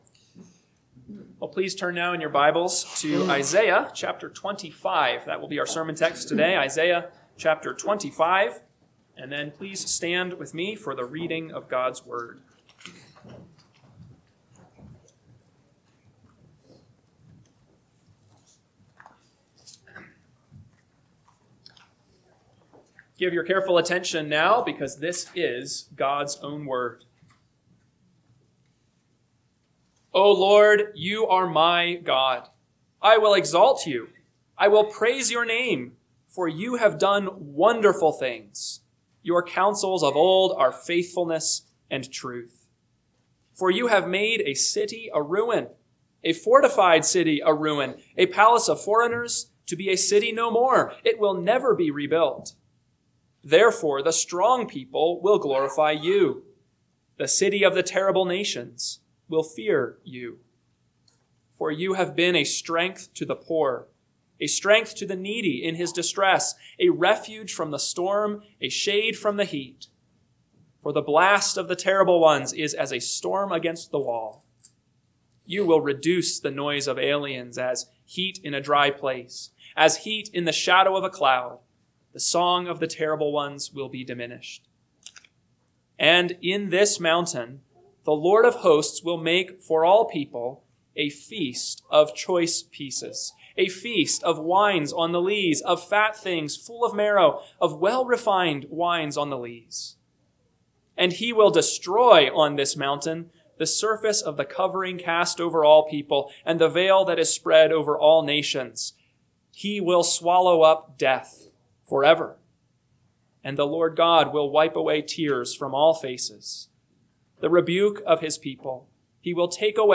AM Sermon – 3/29/2026 – Isaiah 25 – Northwoods Sermons